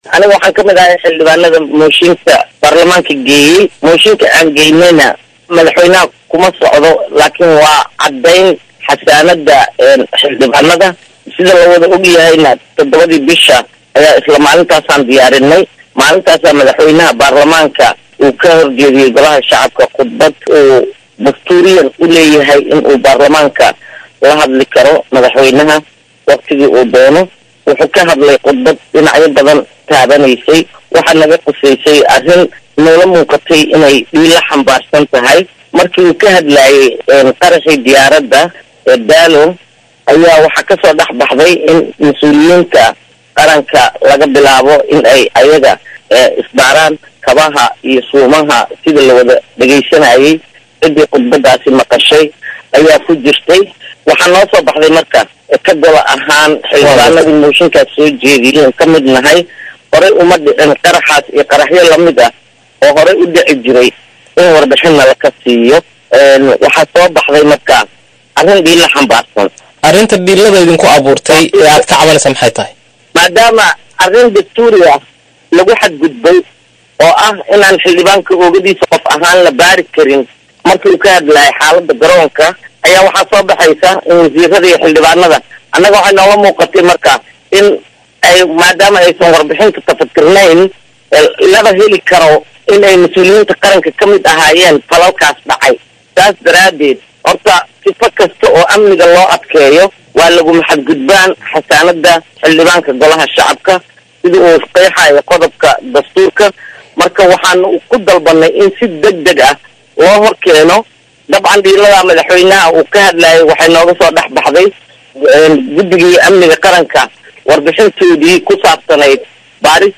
Khadiijo Maxamed Diiriye oo wareysi siiyay VOA-da ayaa sheegtay in Khudbadii Madaxweynaha uu ka jeediyay maalintii Axadii ay aheyd mid dhiilo xambaarsan, isla markaana ku saabsaneyn in baaritaanka laga bilaabo Mas’uuliyiinta, iyagoo kabaha iyo suunka laga siibayo.
Wareysi-Khadiijo-Maxamed-Diiriye.mp3